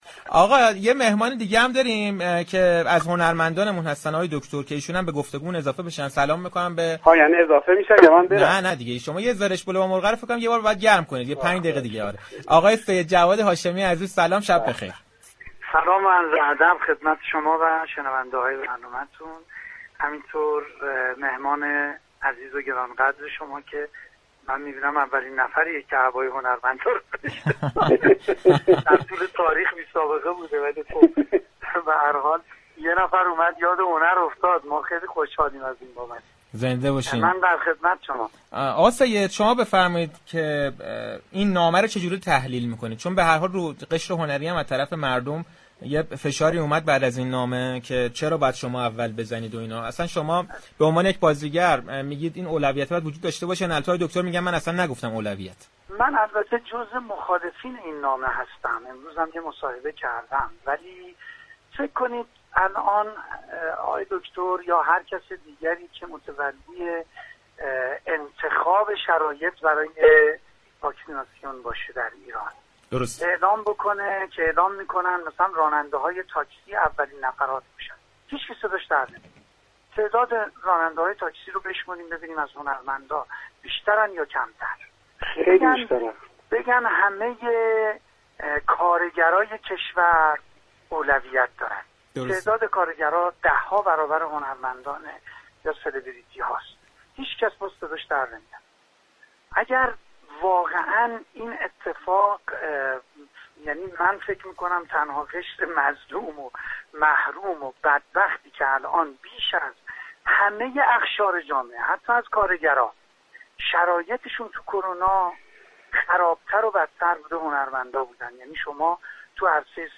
سیدجواد هاشمی با برنامه صحنه رادیو تهران درباره نامه دكتر كرمانپور، سریال دادِستان ده نمكی، تبلیغ اخیرش برای یك شركت و تزریق واكسن كرونا توسط شهاب حسینی در آمریكا گفتگو كرد.